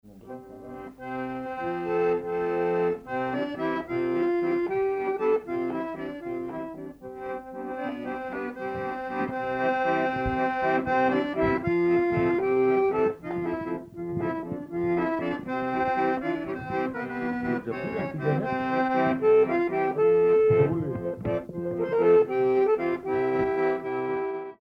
Notre-Dame-de-Bellecombe
Pièce musicale inédite